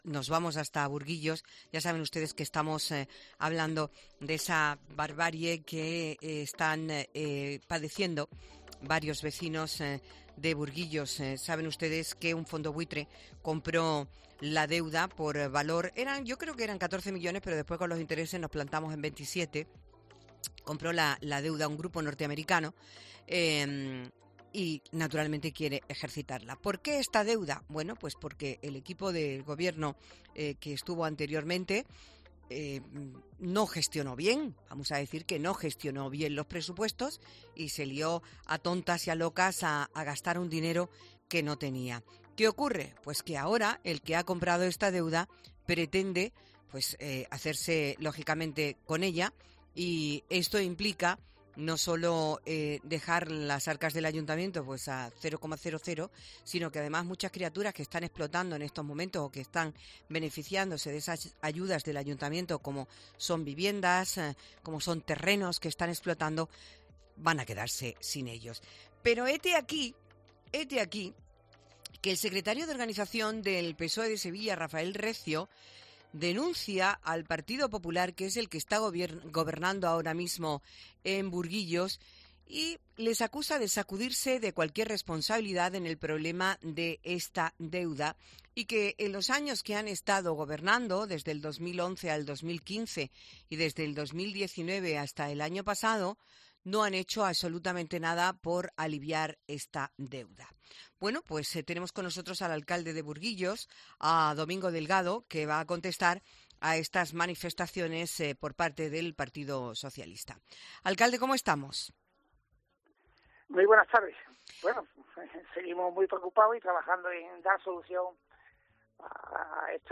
El alcalde de Burguillos ha asegurado indignado a los micrófonos de Herrera en Cope Más Sevilla que "no cabe mayor desfachatez" porque el PSOE en vez de pedir perdón no se ha preocupado de saber que cuando llegó al gobierno en 2011 la deuda era de más de 63 millones y que la rebajó en 15 millones hasta 2015.